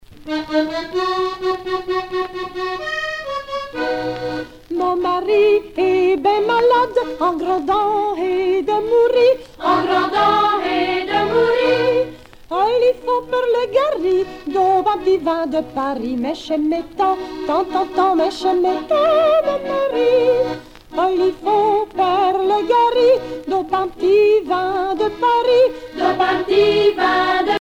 Genre laisse Artiste de l'album Pibolous de la Mothe-Saint-Héray (Les)
Pièce musicale éditée